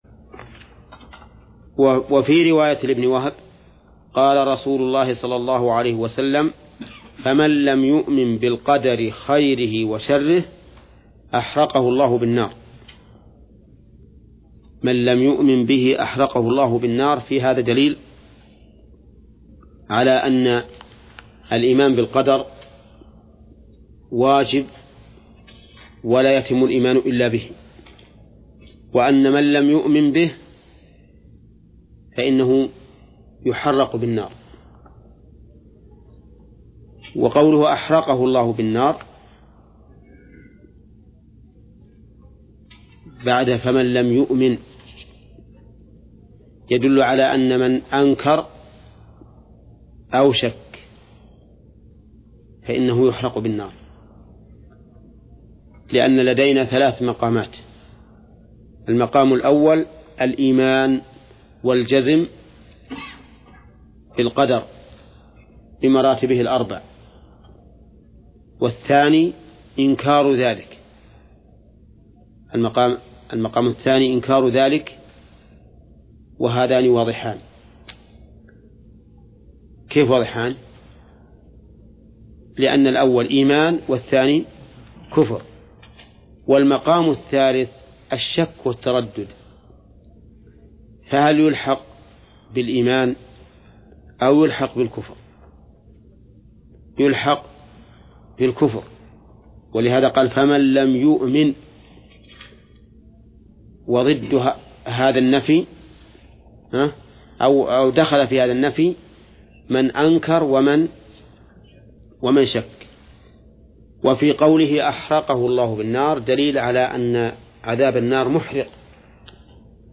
درس(50) / المجلد الثاني : من صفحة: (425)، قوله: (وفي رواية لابن وهب: ..).، إلى صفحة: (446)، قوله: (ولمسلم عن أبي الهياج: ..).